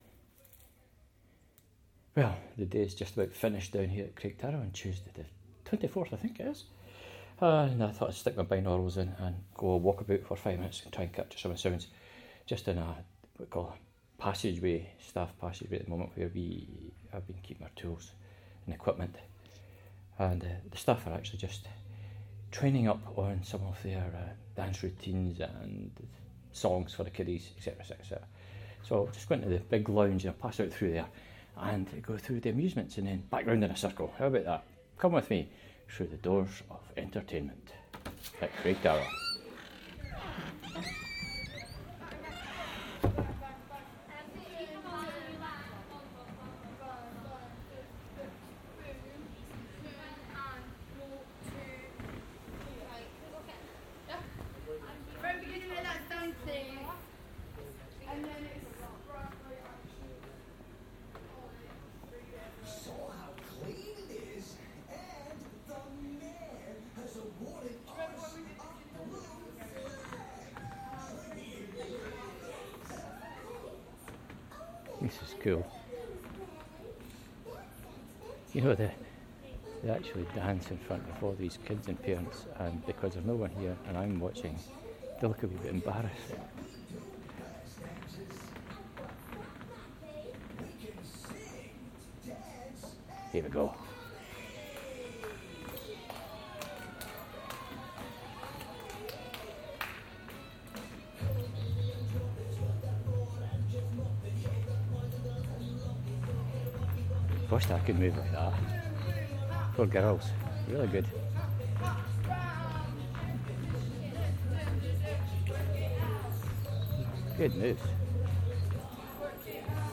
Sounds from Craig Tara 3 - a Binaural recording.